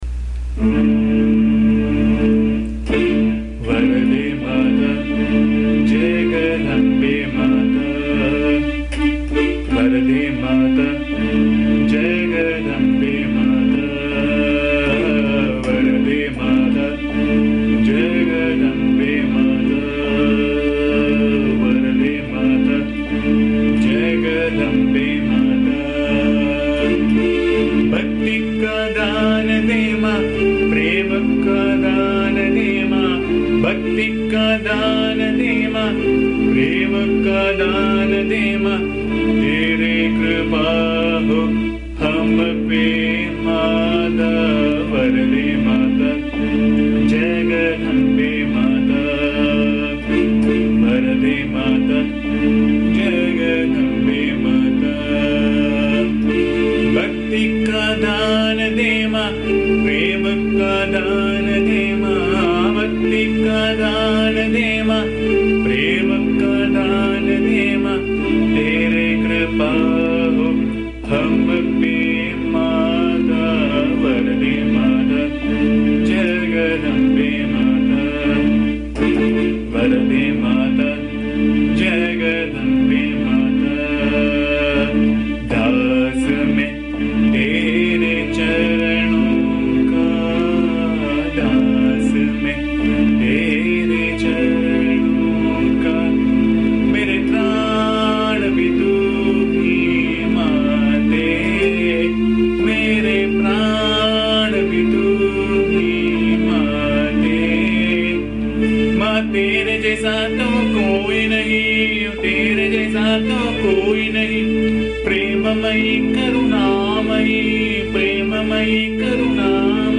This is a beautiful song set in Abheri Raga. It is a very touching song speaking about the grace of the divine mother AMMA - it evokes complete surrender, devotion, love and prostrations to AMMA which in turn gives the seeker "complete cessation of sorrow" and "peace of mind".
The song has been recorded in my voice which can be found here.
AMMA's bhajan song